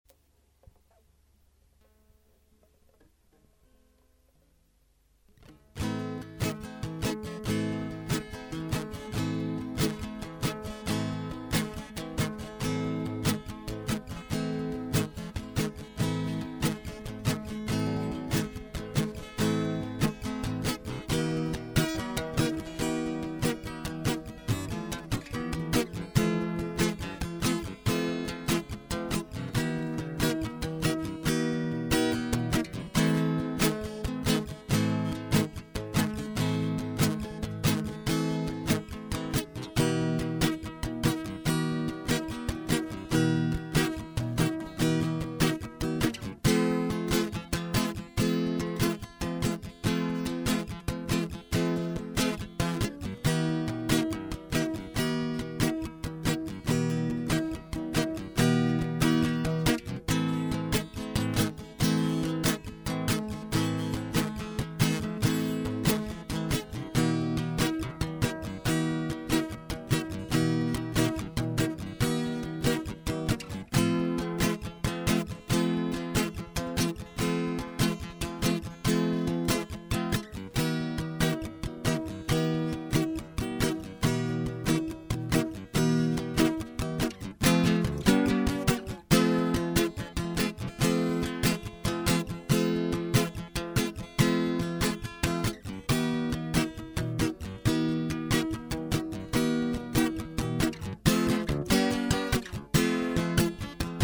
Запись акустической гитары, Проблема стереопространства.